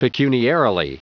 Prononciation du mot pecuniarily en anglais (fichier audio)
Prononciation du mot : pecuniarily